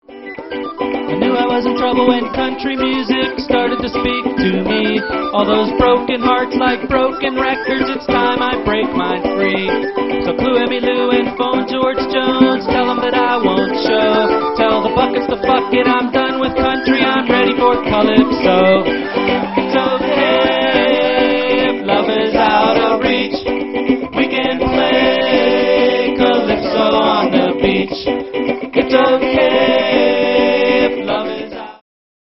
violin & acoustic guitar
bongos and rice
live at Komotion, San Francisco